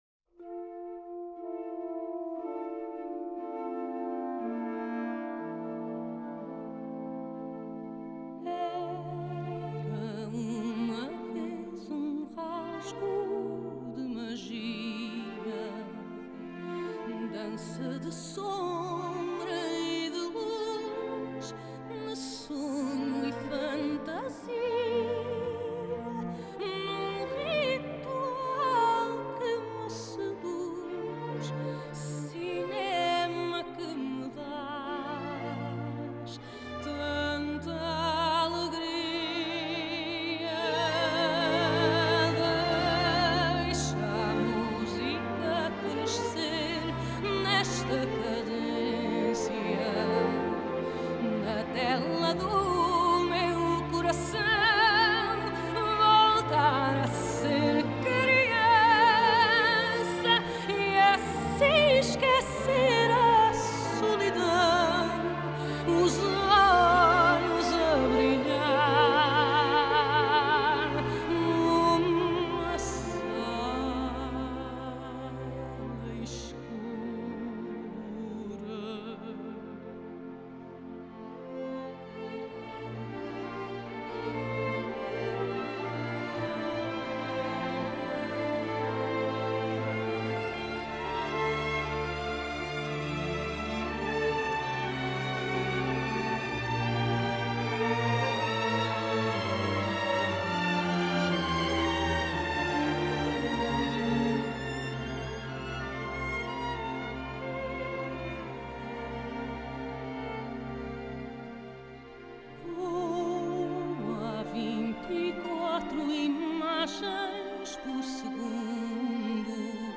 Genre: Score
編寫全新的管弦樂曲